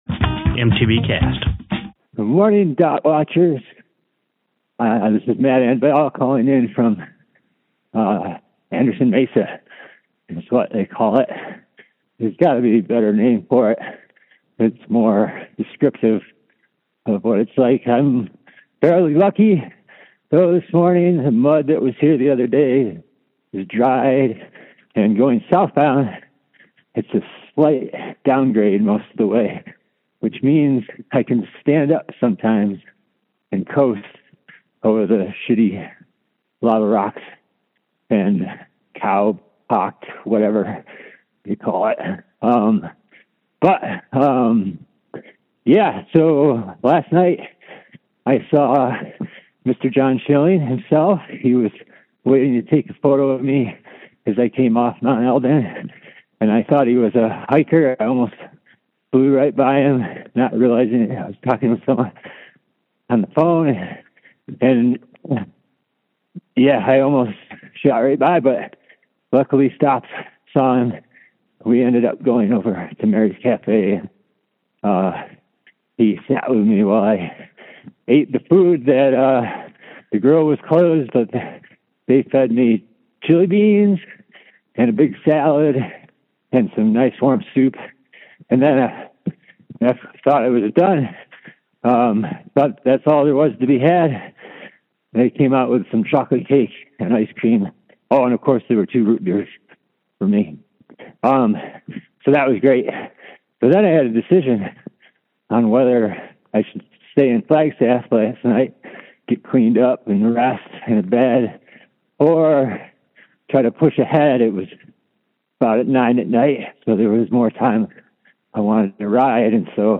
Posted in AZT25 , Calls Tagged AZT25 , bikepacking , Call Ins , calls , endurance racing permalink